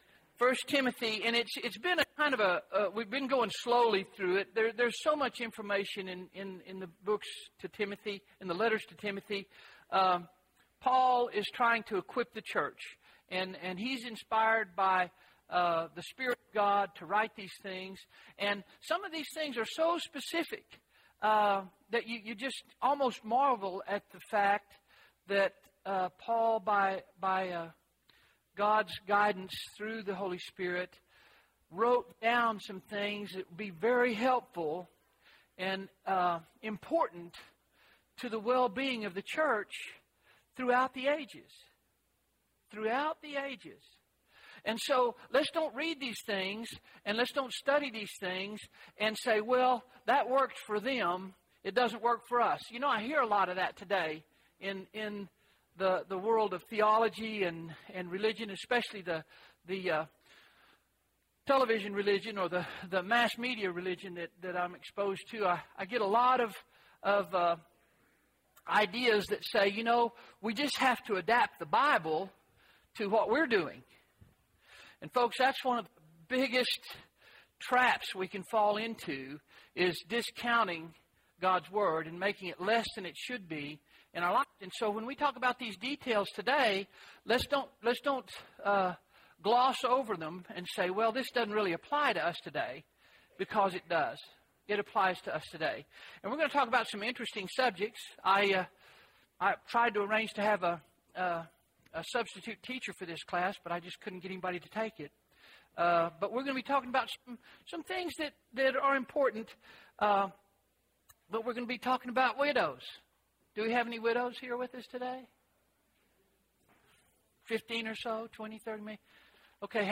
A Study of the Book of 1 Timothy (9 of 12) – Bible Lesson Recording
Sunday AM Bible Class